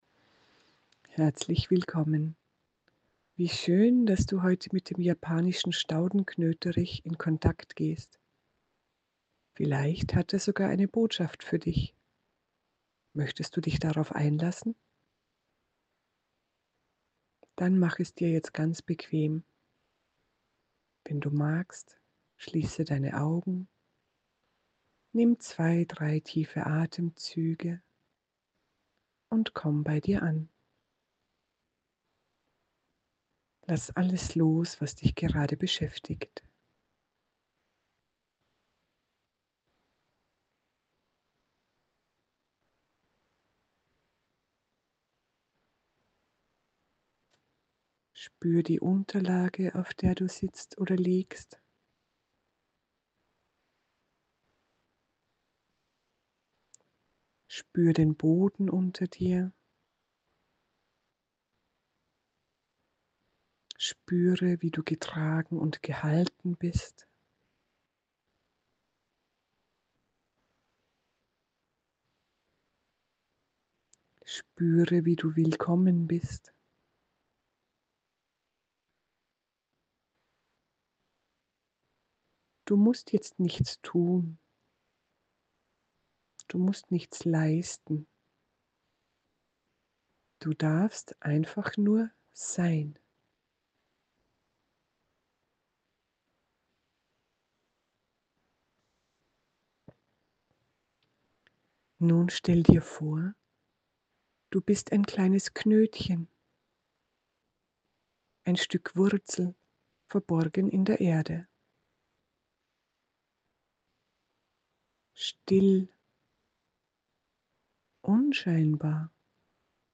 Ich stelle dir dazu kostenlos eine meditative Pflanzenreise zur Verfügung: Download Pflanzenkontakt Staudenknöterich (Dauer: ca. 10 Minuten)